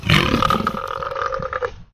slave_alert.ogg